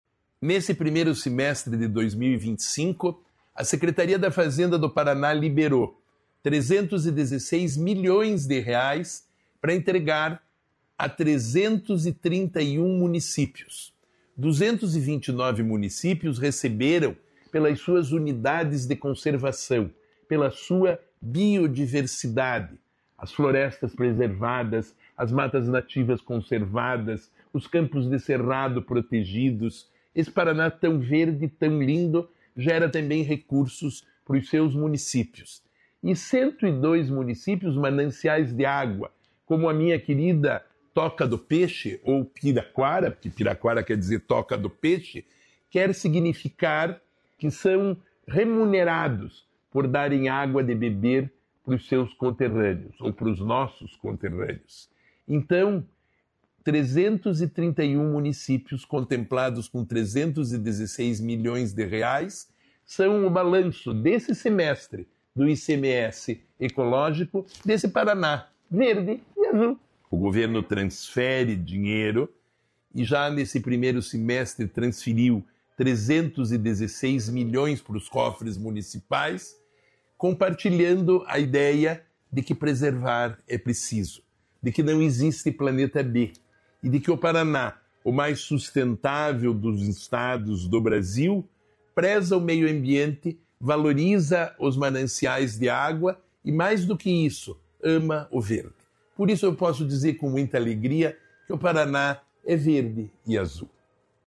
Sonora do secretário estadual do Desenvolvimento Sustentável, Rafael Greca, sobre o ICMS ecológico